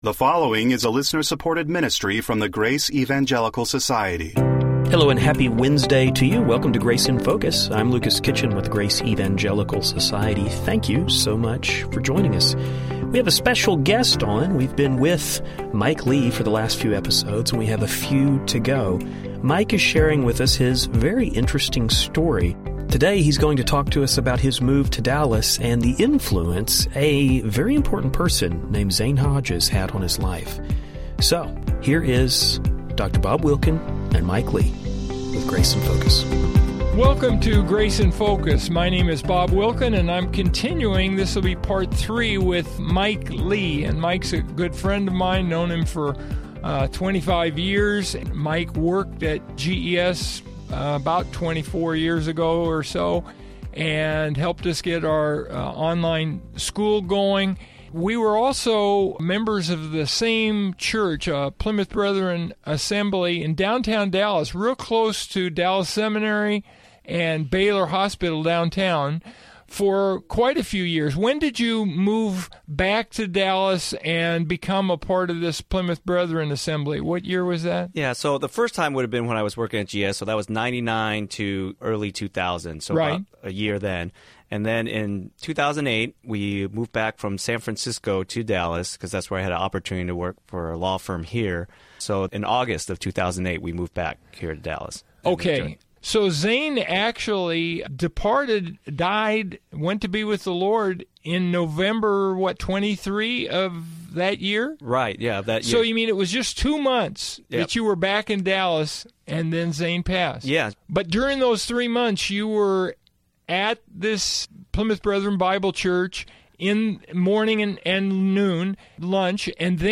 We hope you find the conversation helpful.